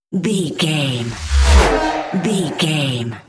Whoosh electronic metal
Sound Effects
futuristic
intense
whoosh